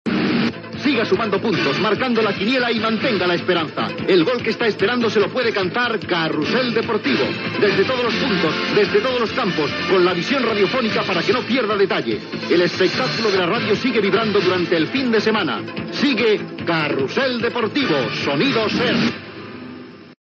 Promoció del programa i dels resultats de "La quiniela"
Esportiu